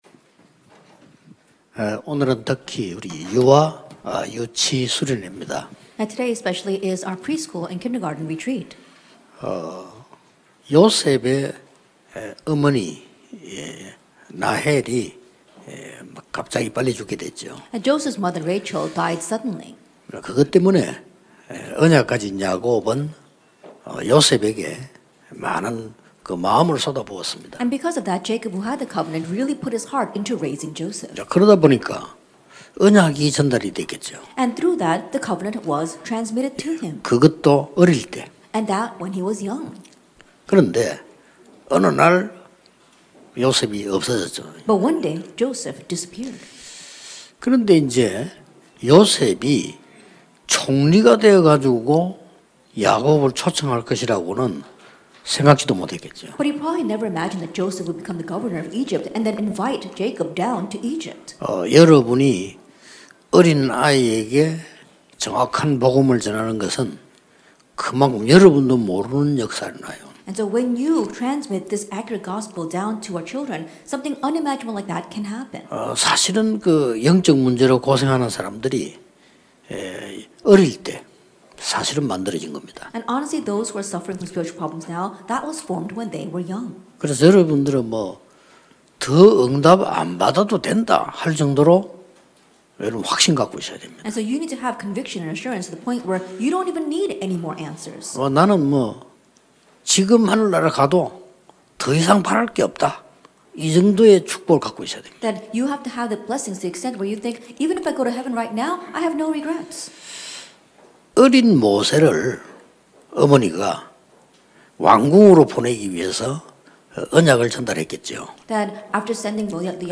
[유아 유치 수련회 1강] 영적인 힘과 전달 (출 2:1-10) 1.